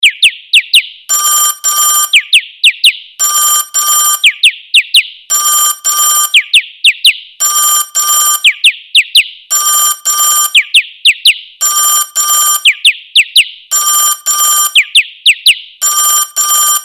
Пение птиц со звонком - на будильник